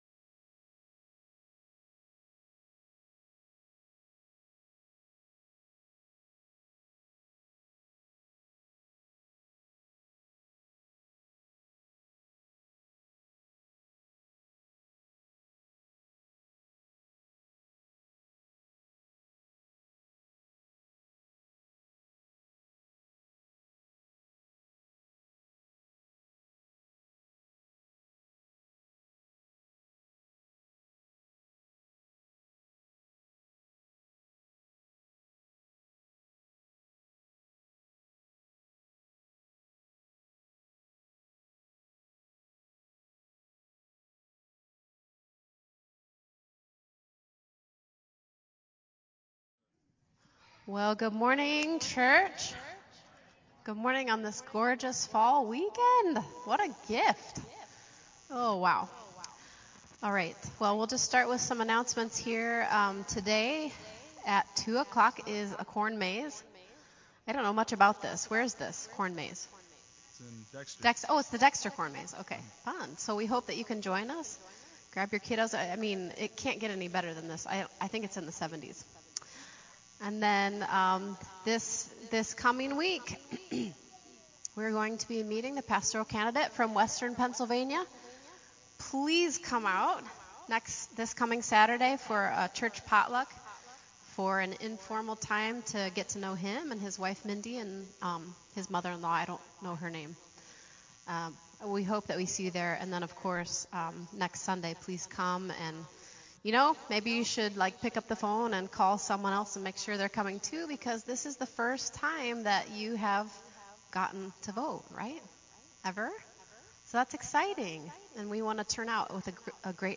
Praise Worship
Announcements